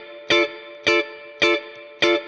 DD_StratChop_105-Dmin.wav